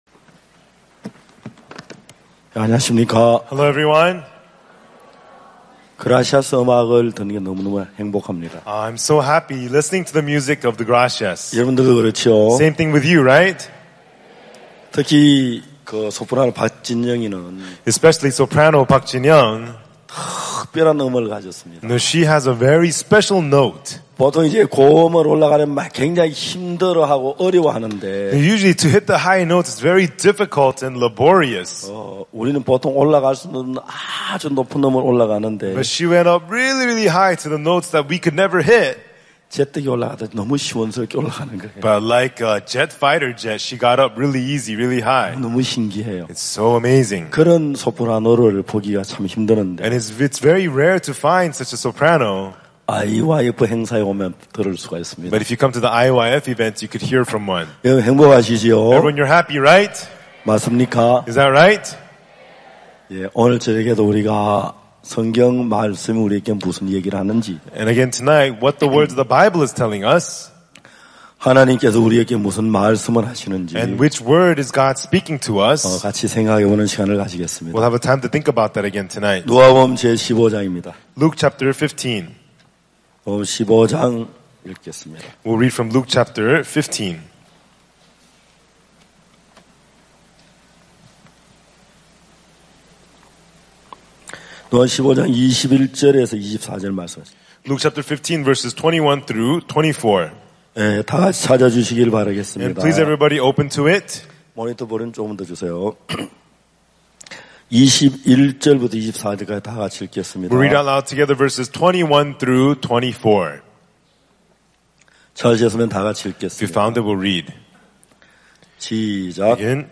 IYF 월드캠프의 주요 프로그램인 마인드 강연. 진정한 삶의 의미를 찾지 못하고 스스로에게 갇혀 방황하는 청소년들에게 어디에서도 배울 수 없는 마음의 세계, 그리고 다른 사람들과 마음을 나누는 방법을 가르친다.